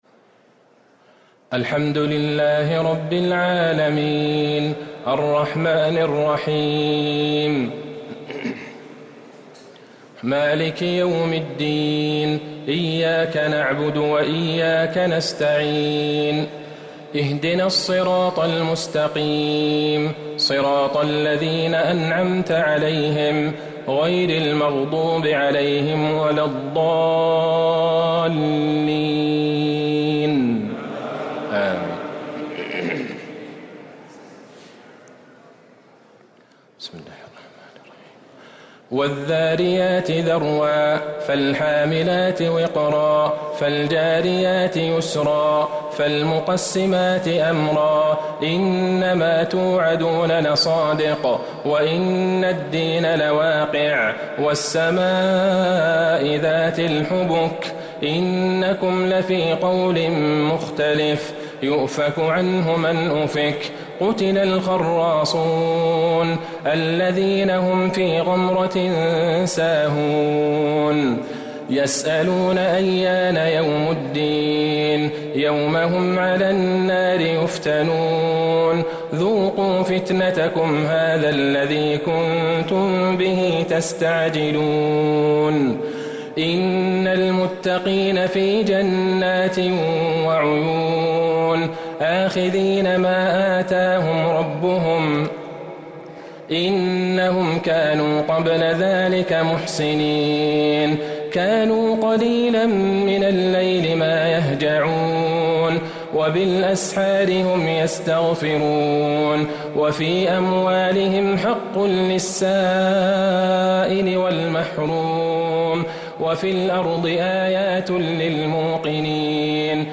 تهجد ليلة 27 رمضان 1444هـ من سورة الذاريات إلى سورة الرحمن (1-45) | Tahajjud 27st night Ramadan 1444H from Surah Adh-Dhariyat from Surah Al-Rahman > تراويح الحرم النبوي عام 1444 🕌 > التراويح - تلاوات الحرمين